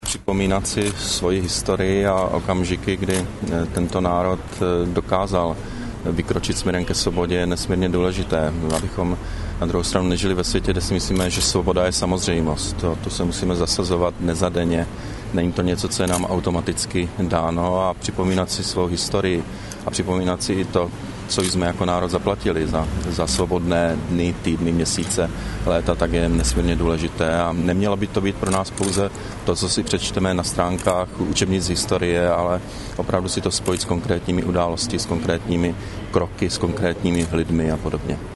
Předseda vlády Petr Nečas spolu s prezidentem Václavem Klausem uctili památku 17. listopadu 1989 na Národní třídě.
„Připomínat si svoji historii a okamžiky, kdy tento národ dokázal vykročit směrem ke svobodě je nesmírně důležité, abychom nežili ve světě, kde si myslíme, že svoboda je samozřejmost, o to se musíme zasazovat dnes a denně,“ řekl premiér novinářům na Národní třídě.
Premier-na-Narodni-tride.mp3